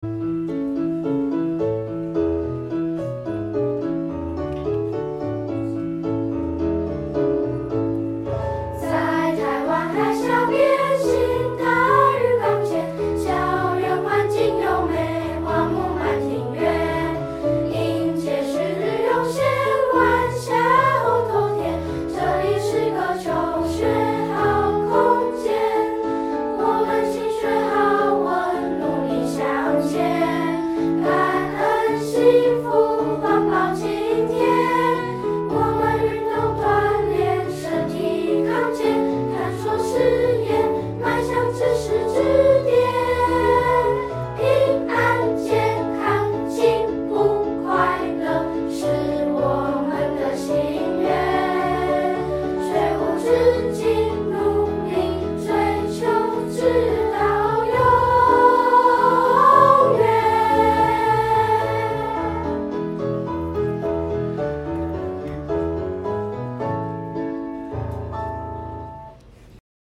校歌_合唱1.mp3.mp3